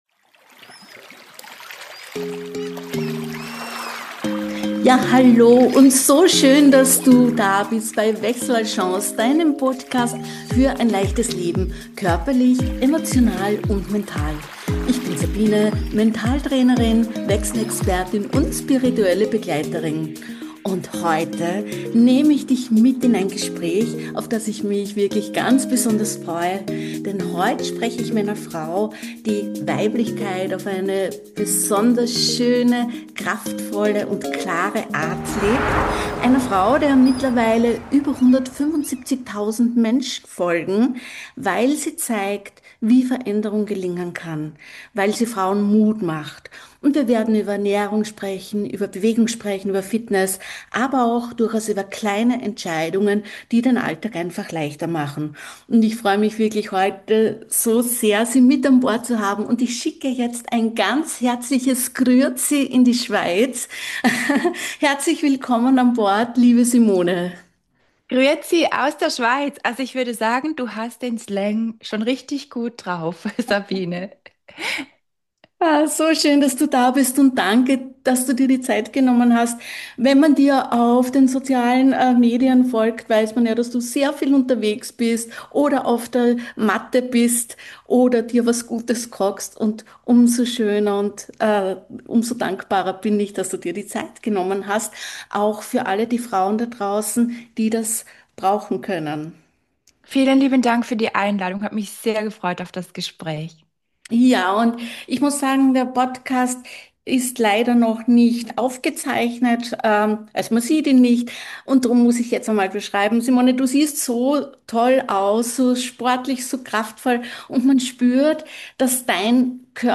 Wir sprechen darüber, warum so viele Frauen im Wechsel an sich zweifeln wie Ernährung Hormone, Energie und Stimmung beeinflusst und warum Frühstück, Protein und Schlaf echte Gamechanger sind. Wir sprechen auch, wie du mit Heißhunger, Zucker & unperfekten Tagen liebevoll umgehen kannst. Dieses Gespräch ist ehrlich, fundiert und mutmachend.